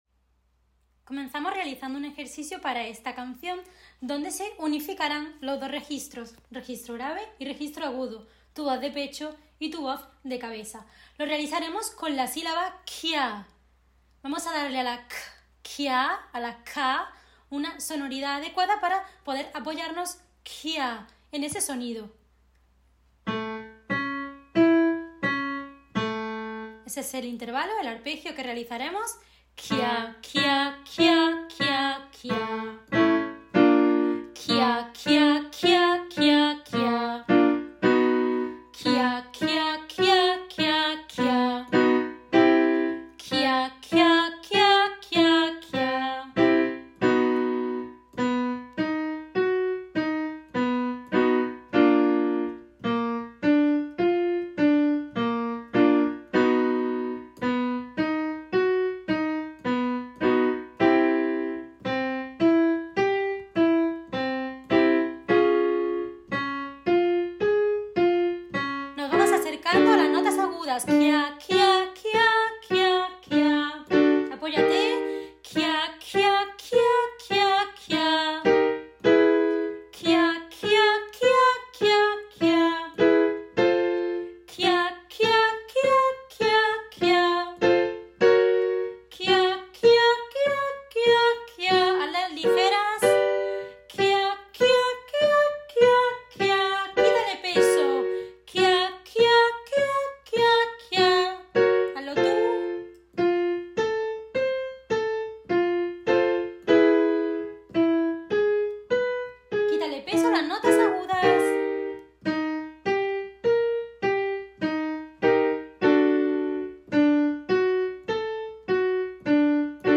Ejercicio de calentamiento vocal KIA.
Para esta canción trabajaremos con las vocales.  Este sencillo ejercicio te permitirá posicionar tu voz en los resonadores y relajar las cuerdas vocales.
Calentamiento vocal sílaba KIA.
TRUCO: Haz un sonido suave sin forzar tu voz a la hora de pronunciar las vocales.
CalentamientoKIA.mp3